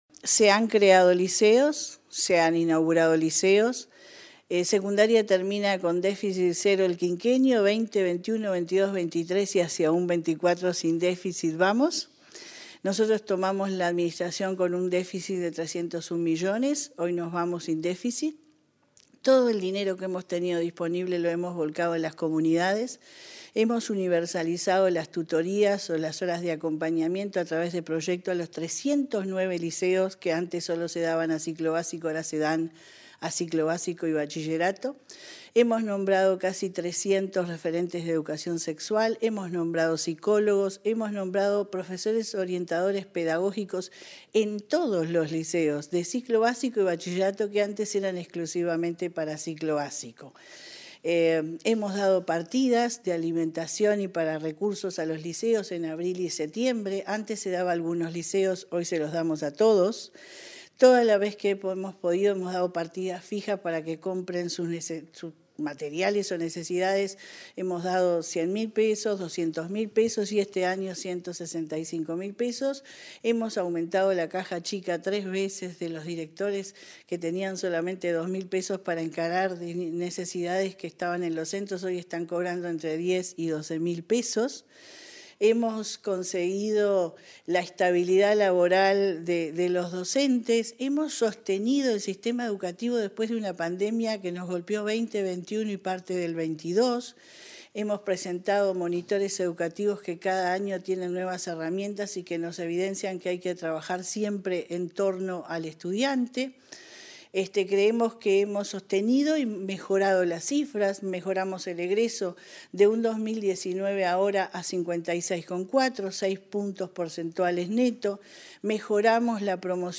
Entrevista a la directora general de Secundaria, Jenifer Cherro